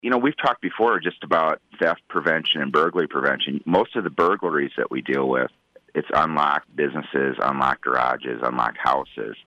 He joins KFJB earlier this week to discuss the uptick.